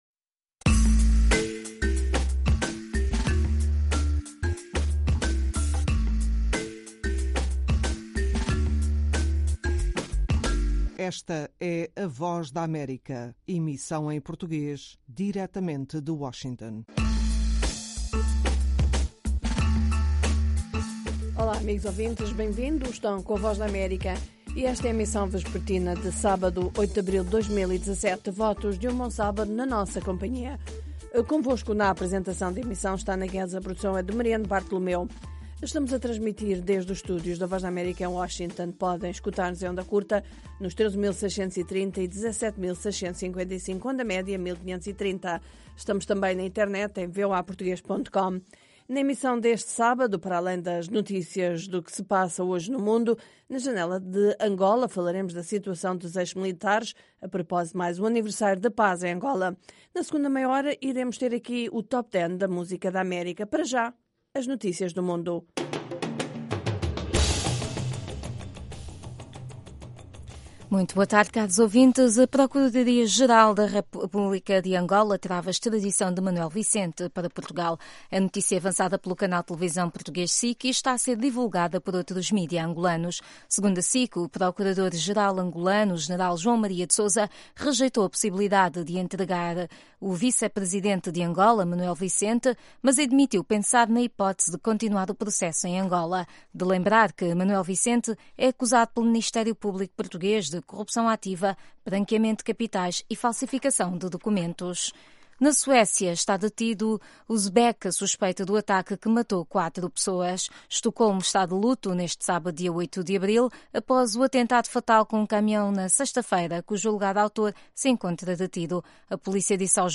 Meia-hora duas vezes por Aos sábados, ouça uma mesa redonda sobre um tema dominante da política angolana, música americana e as notícias do dia.